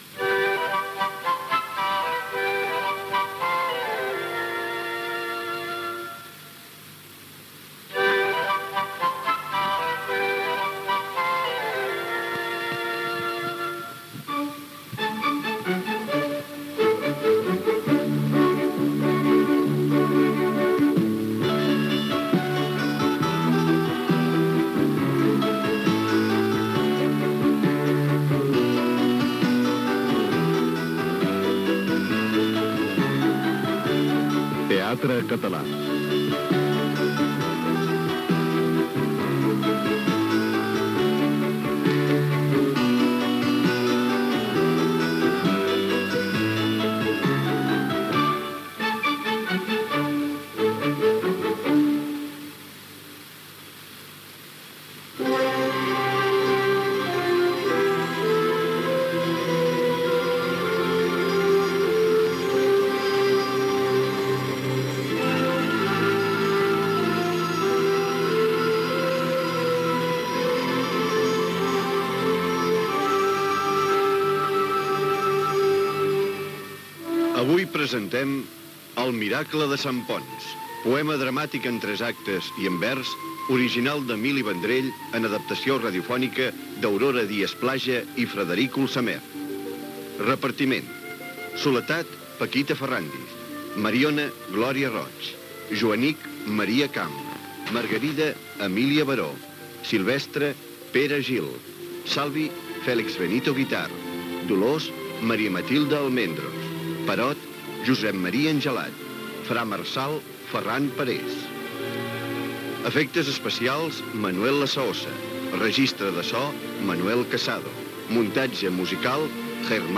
Sintonia de Ràdio 4. Adaptació de l'obra "El miracle de Sant Ponç", d'Emili Vendrell. Careta del programa, repartiment. Diàleg entre mare i filla. Joanic i Mariona juguen.
Ficció